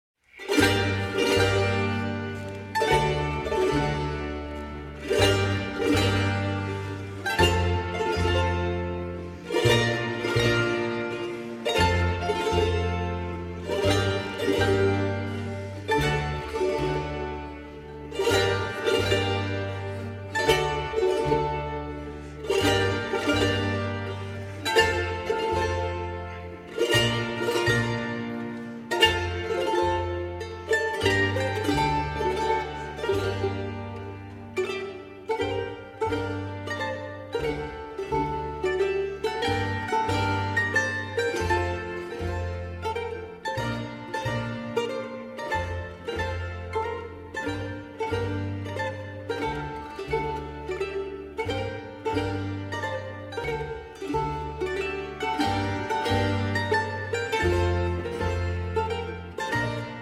Early music by Sanz
Chamber Ensemble